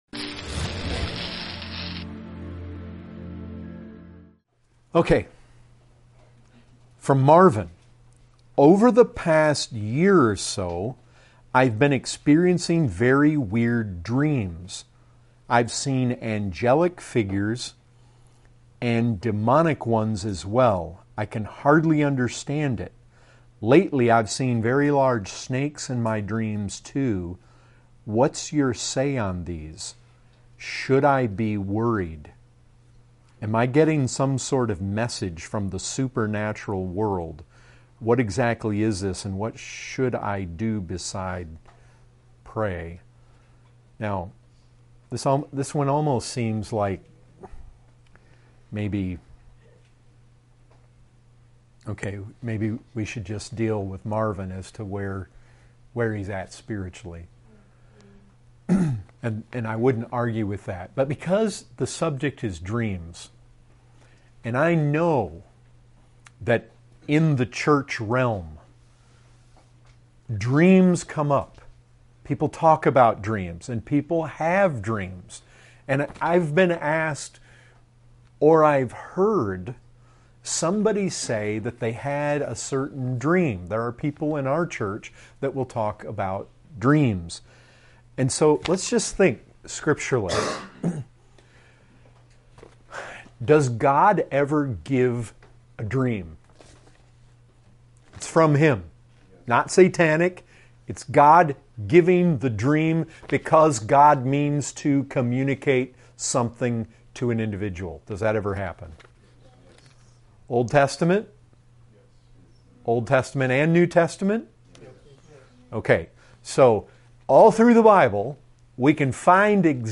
of Grace Community Church